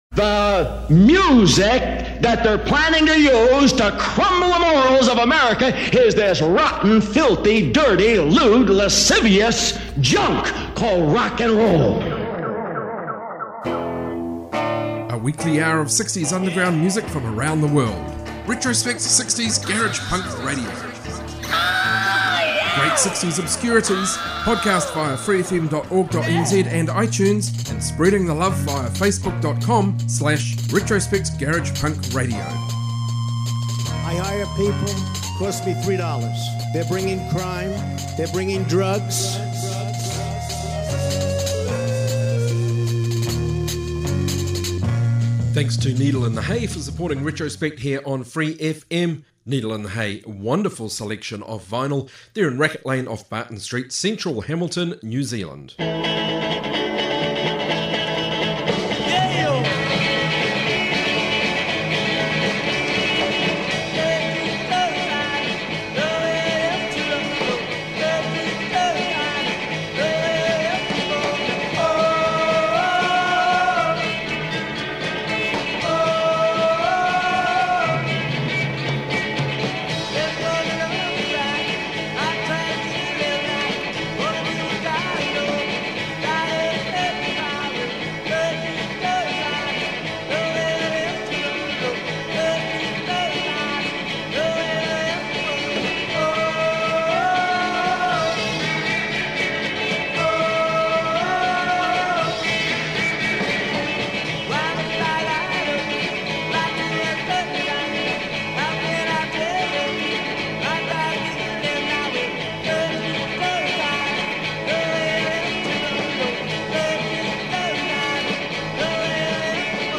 60s garage show